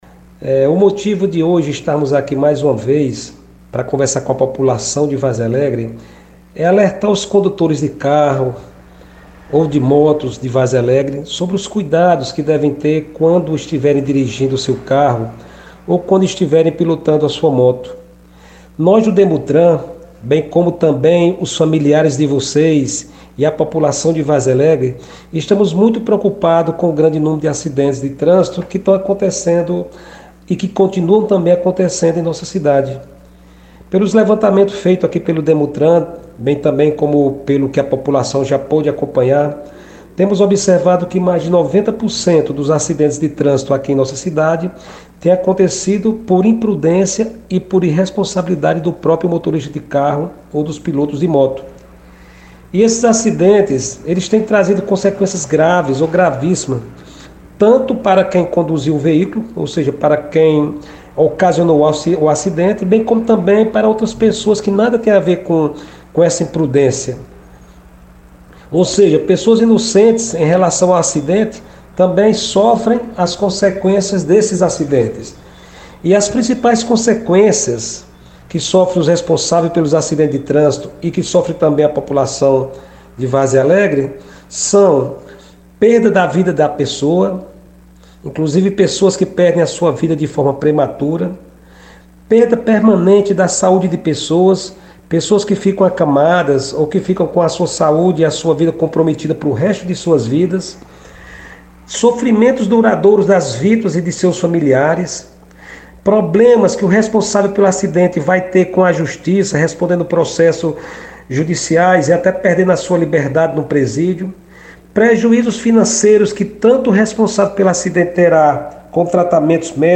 O agente enviou um áudio explicando a situação e trazendo orientações para condutores de motocicletas e motoristas.